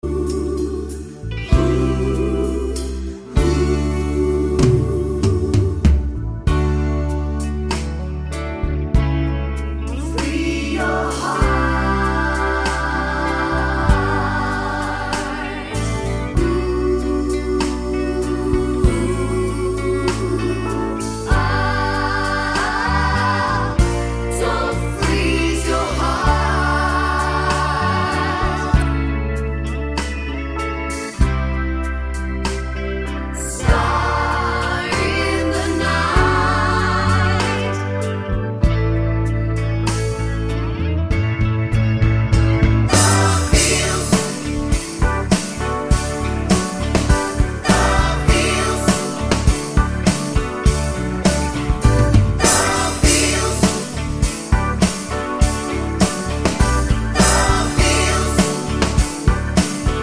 no Backing Vocals Musicals 4:37 Buy £1.50